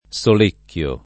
[ S ol % kk L o ]